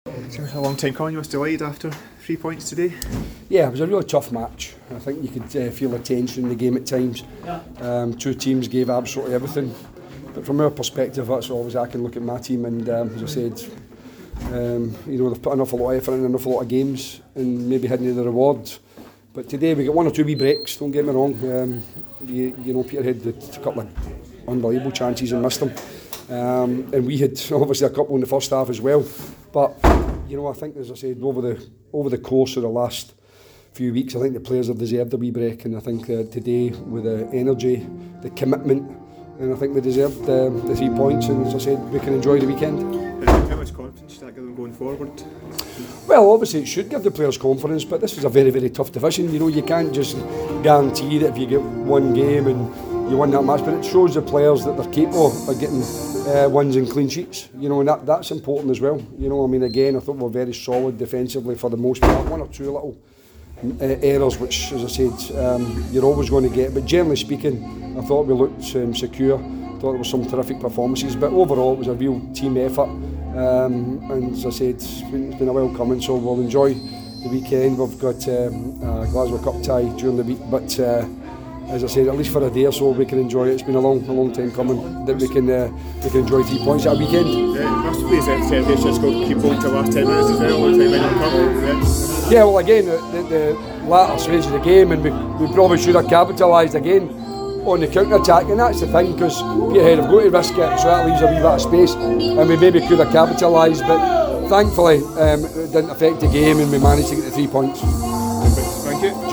Jim Duffy's post-match comments following the cinch League 1 fixture